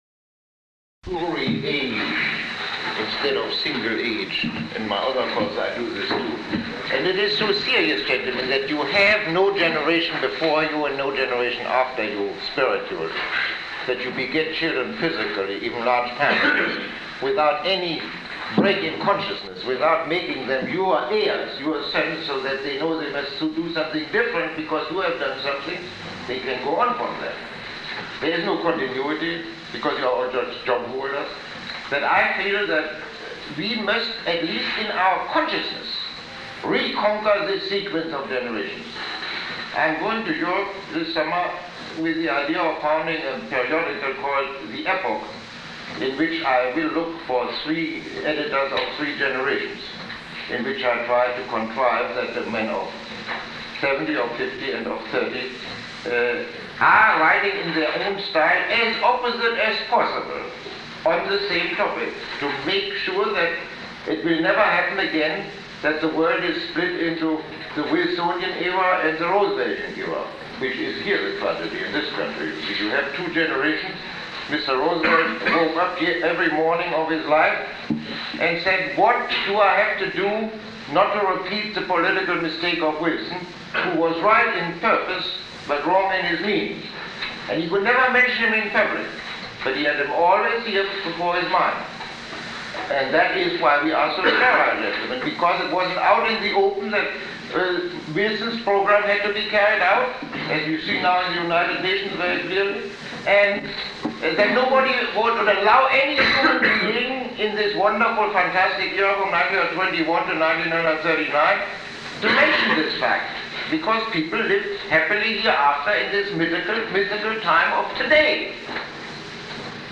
Lecture 11